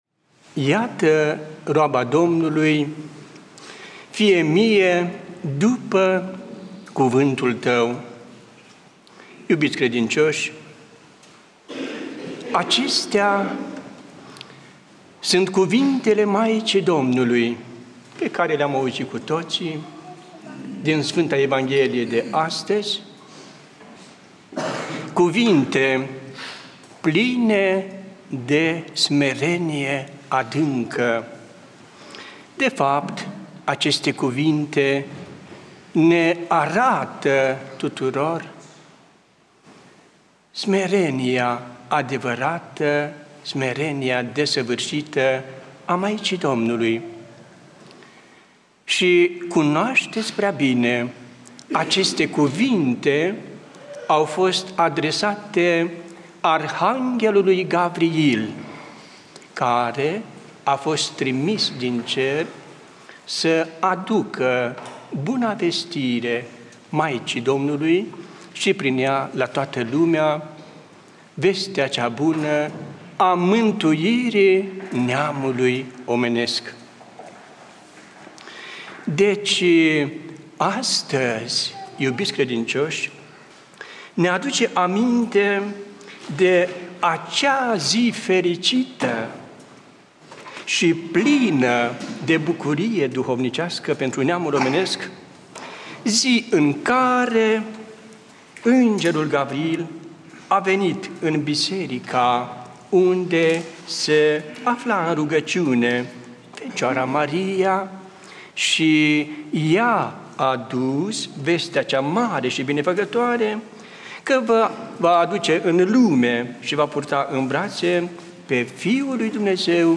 Predică PF Daniel